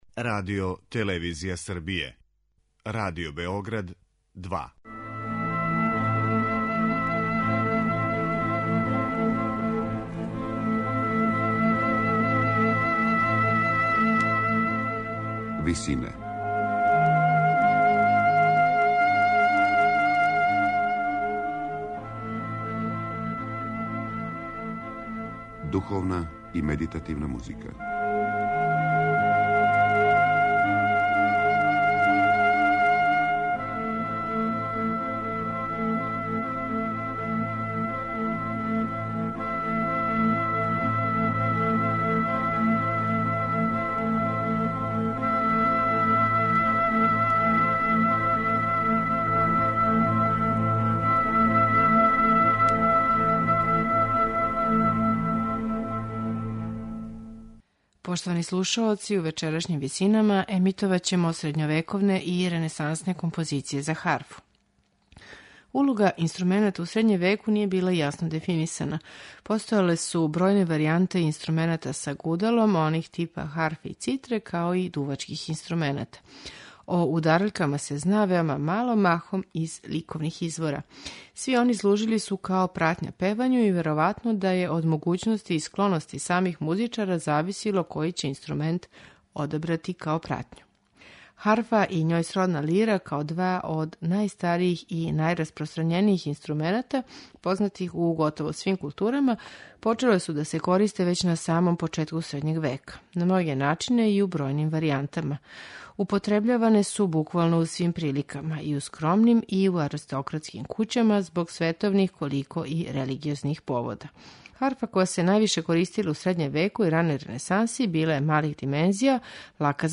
Духовни напеви и баладе средњовековних и ренесансних аутора